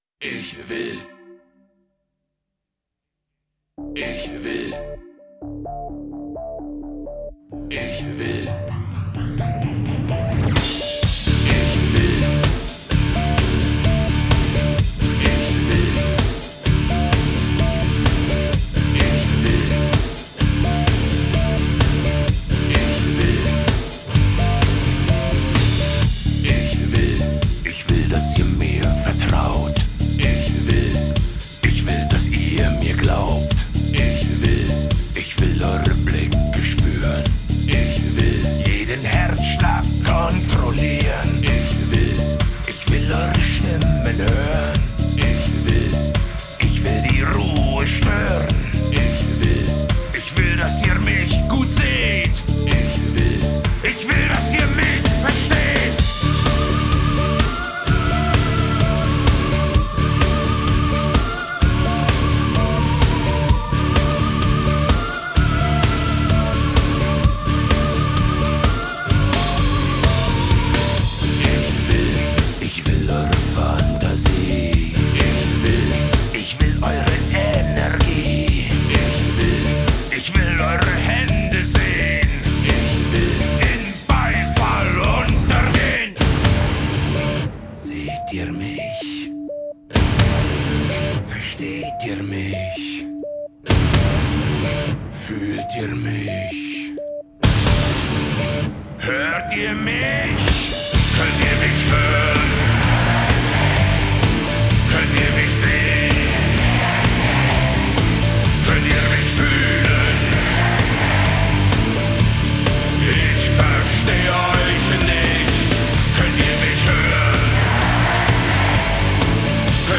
Жанры: Neue Deutsche Herte, индастриал-метал,
хард-рок, готик-метал